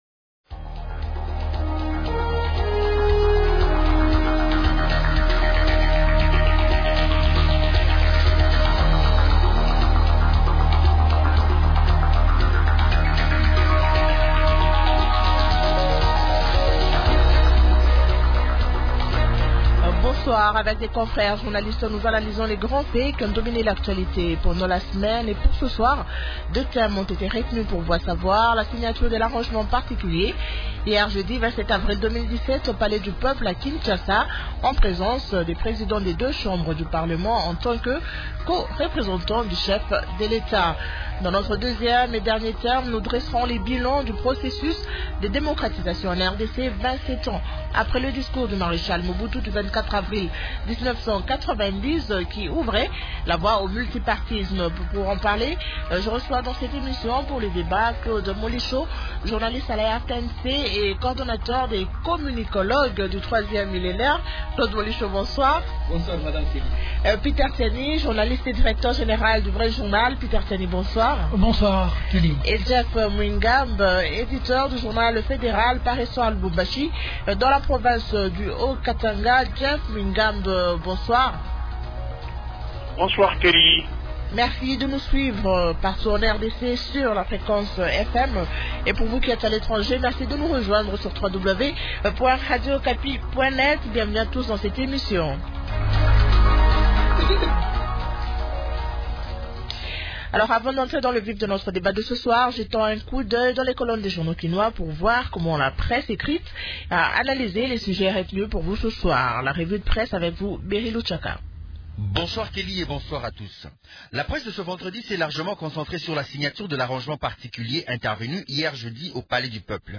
Ce sont les deux thèmes de la Tribune de Presse du jour.